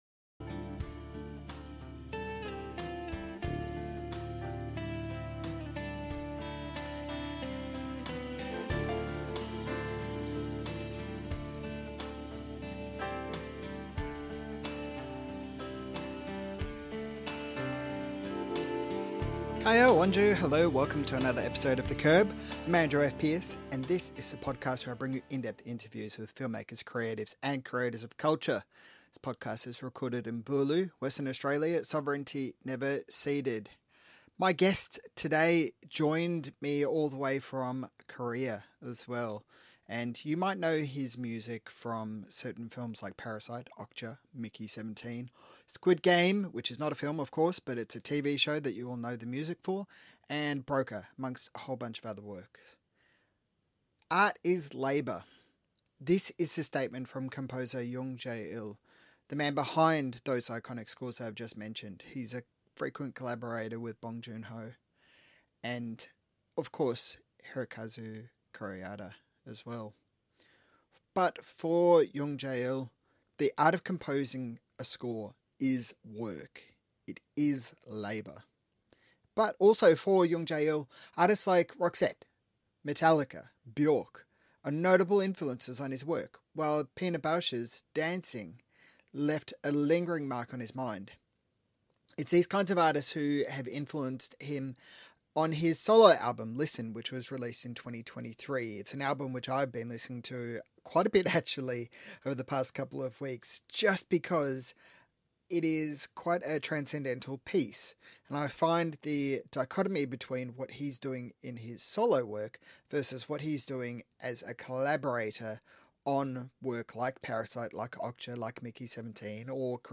These are just some of the things discussed in the above interview with Jung Jae-il, recorded ahead of his performance of the live score for Parasite at the 2025 Melbourne International Film Festival.